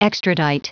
Prononciation du mot extradite en anglais (fichier audio)
Prononciation du mot : extradite